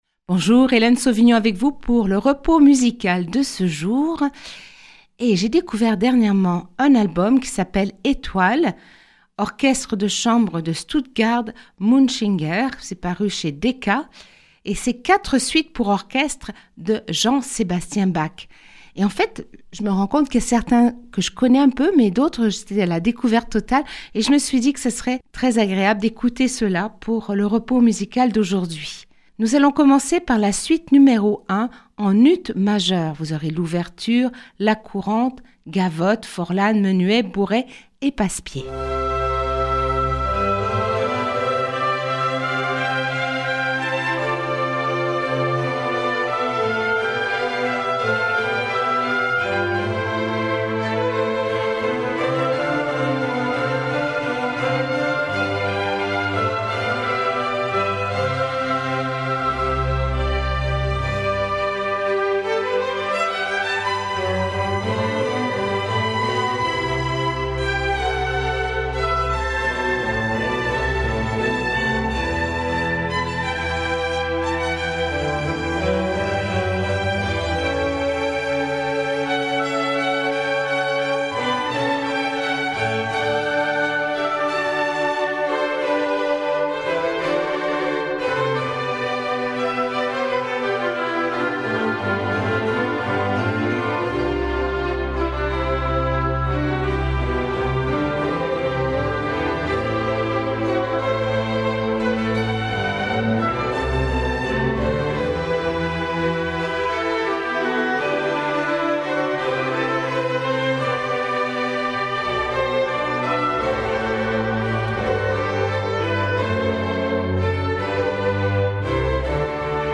QUATRE SUITES POUR ORCHESTRE JS BACH